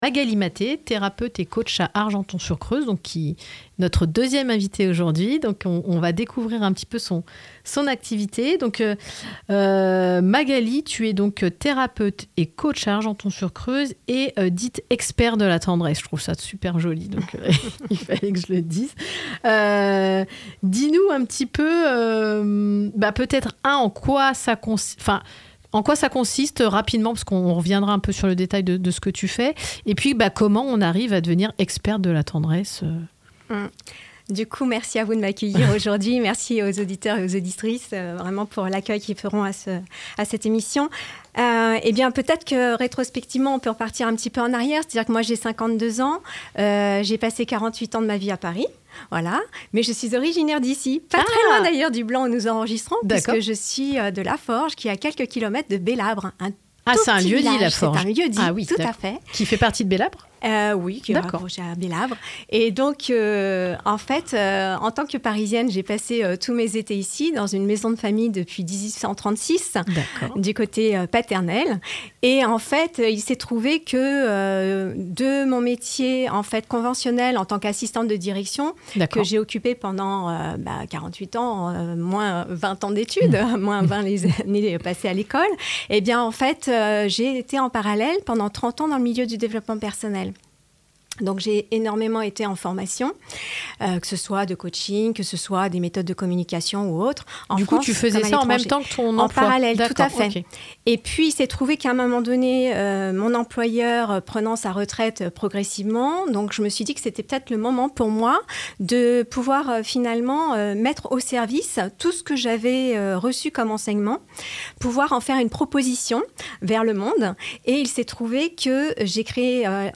Interview
ITW-RADIO-DYNAMO.mp3